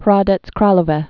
(hrädĕts krälō-vĕ)